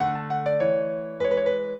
piano
minuet6-6.wav